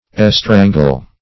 Estrangle \Es*tran"gle\